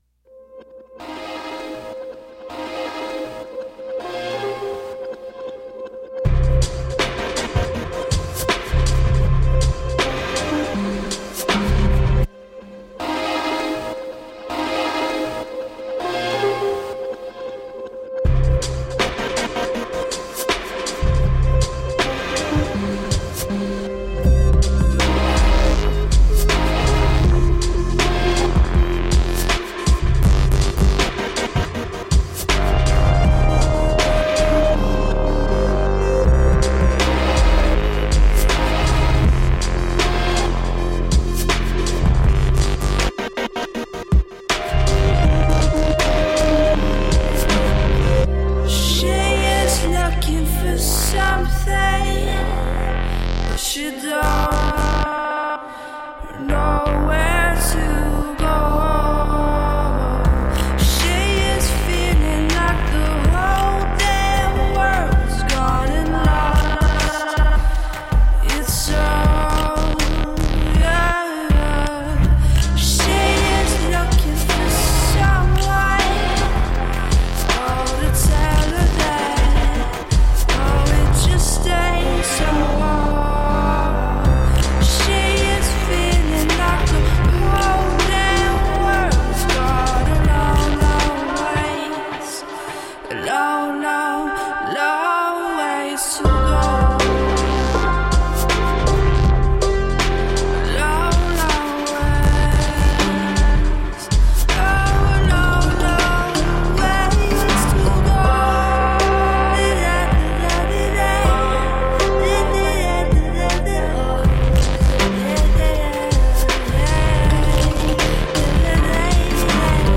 Electro-acoustic in perfect accord.
Big warm bass and sophisticated beats
Tagged as: Electronica, Other